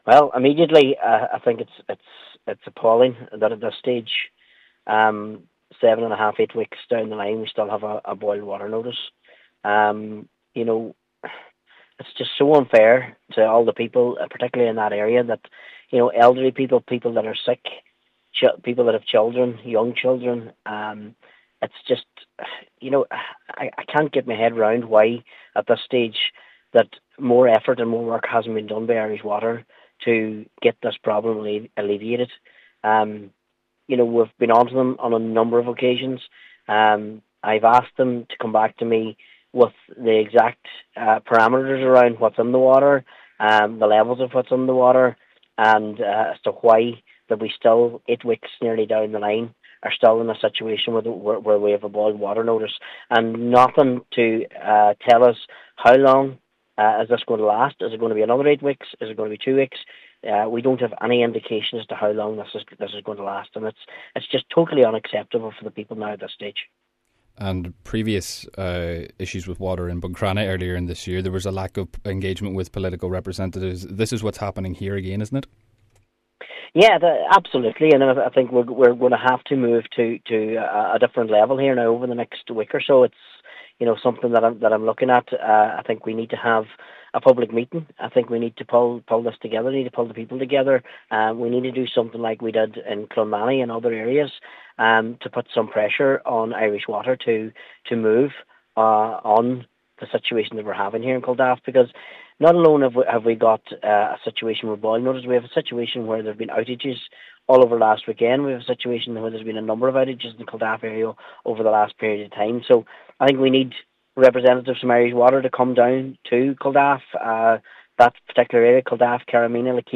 Cllr Martin McDermott says that Donegal County Council no longer having a role in water is a backwards step when issues like this appear: